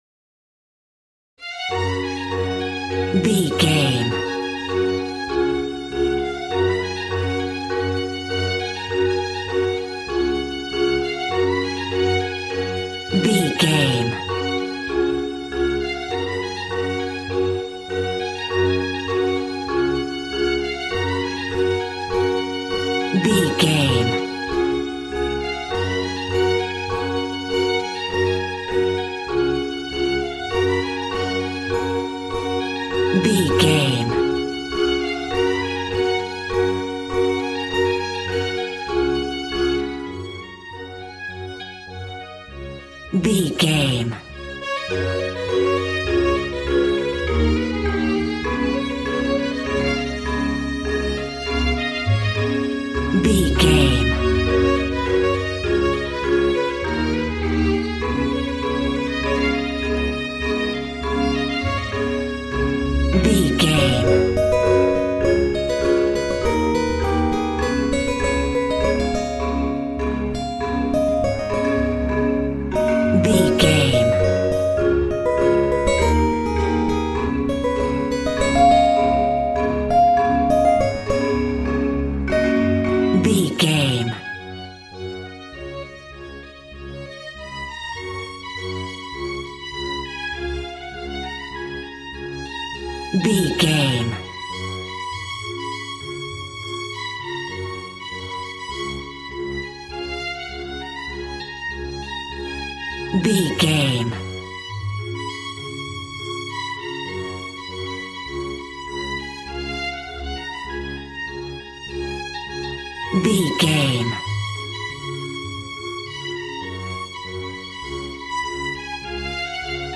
Ionian/Major
G♭
positive
cheerful/happy
joyful
drums
acoustic guitar